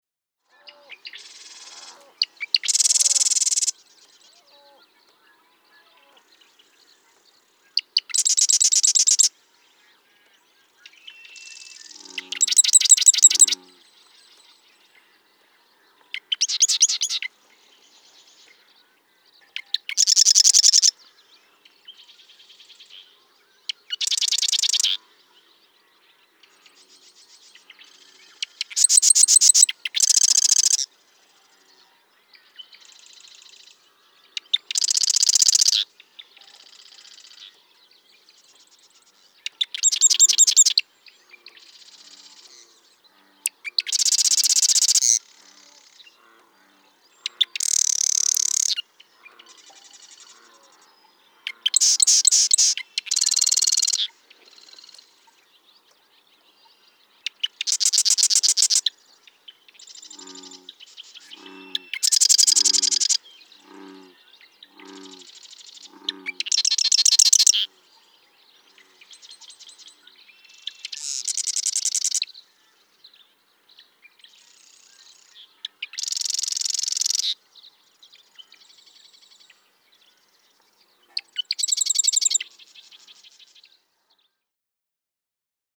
Marsh wren
♫229. Song matching, example 2; focus on the seven matches that occur during the following times: 0:00 to 0:03, 0:21 to 0:25, 0:26 to 0:31, 0:34 to 0:37, 0:41 to 0:45, 0:45 to 0:49, and 1:18 to 1:22.
Lee Metcalf National Wildlife Refuge, Stevensville, Montana.
229_Marsh_Wren.mp3